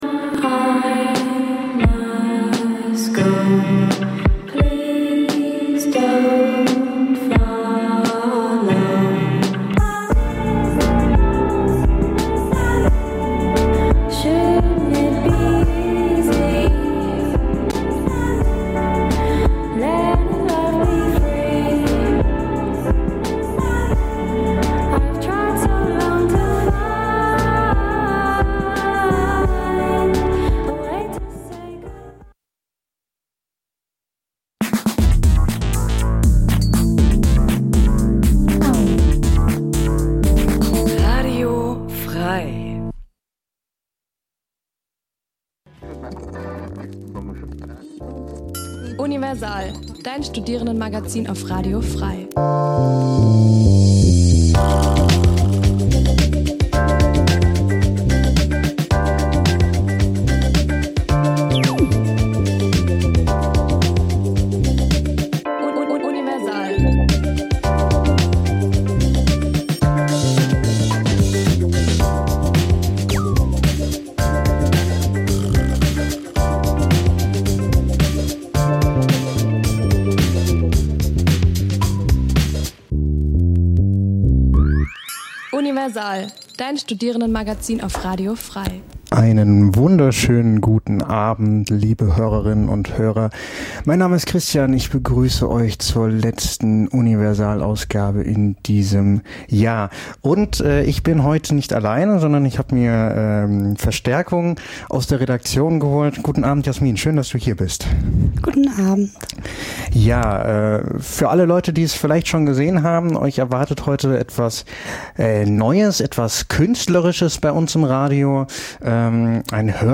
Die Sendungen werden gemeinsam vorbereitet - die Beitr�ge werden live im Studio pr�sentiert.